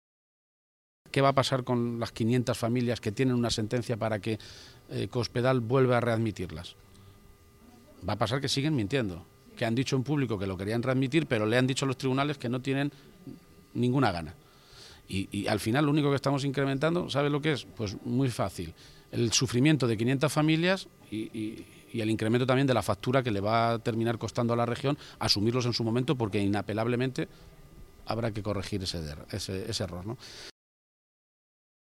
García-Page se pronunciaba de esta manera esta mañana, en Toledo, en unas declaraciones a los medios de comunicación en las que recordaba que la propia Cospedal había dicho públicamente que readmitiría a los interinos, incluso con el recurso que la Junta ha planteado ante el Tribunal Supremo contra la sentencia del TSJCM.
Cortes de audio de la rueda de prensa